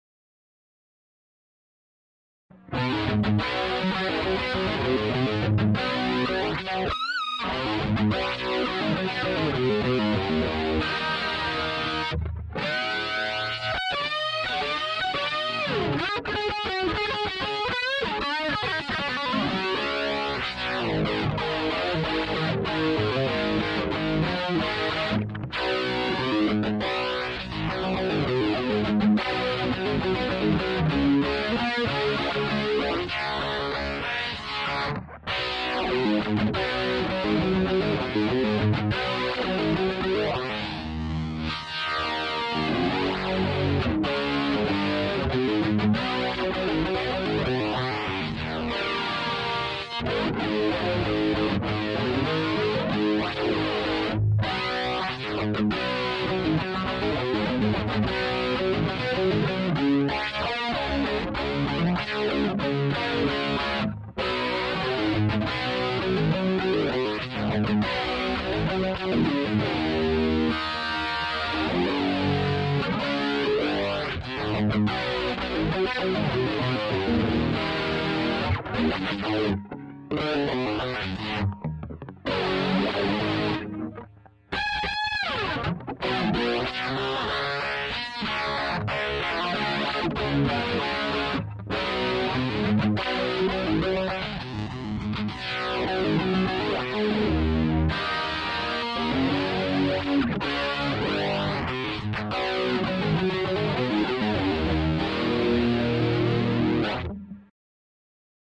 I recently picked up an Epiphone Les Paul 100 and a Gibson case.
Here is a clip that I made using the same dual flanger setup that I used in my last clip.